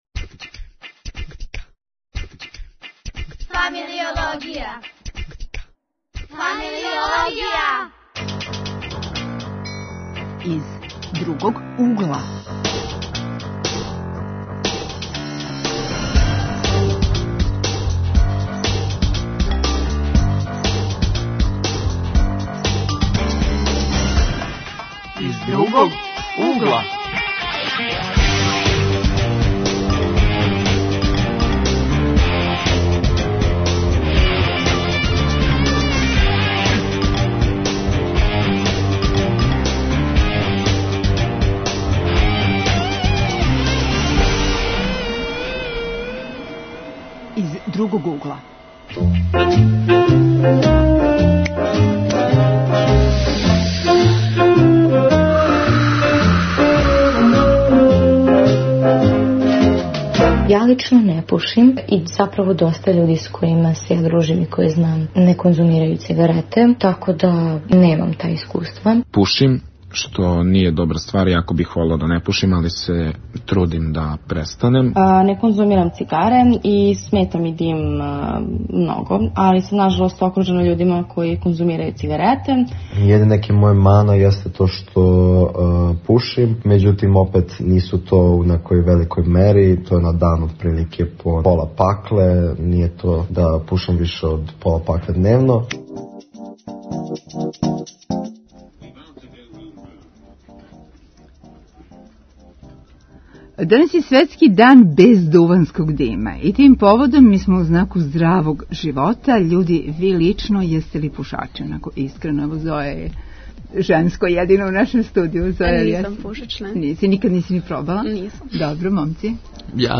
Гости су студенти